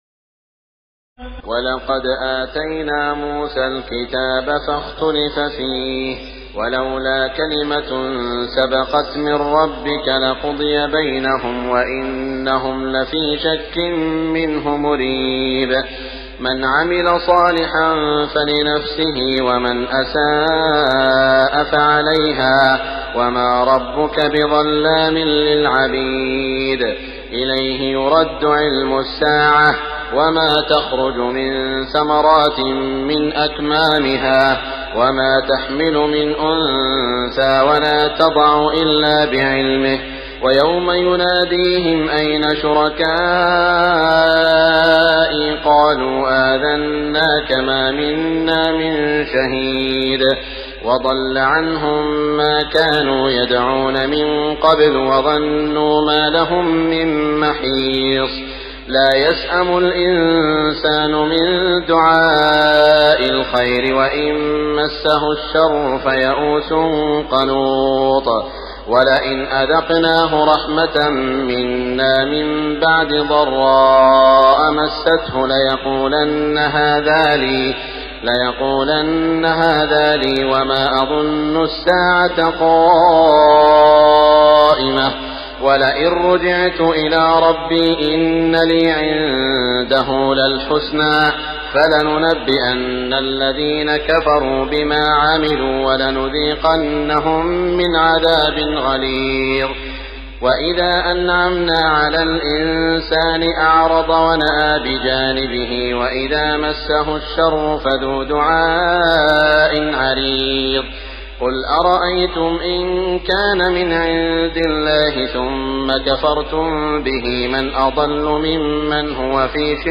تراويح ليلة 24 رمضان 1418هـ من سور فصلت (45-54) و الشورى و الزخرف (1-25) Taraweeh 24 st night Ramadan 1418H from Surah Fussilat and Ash-Shura and Az-Zukhruf > تراويح الحرم المكي عام 1418 🕋 > التراويح - تلاوات الحرمين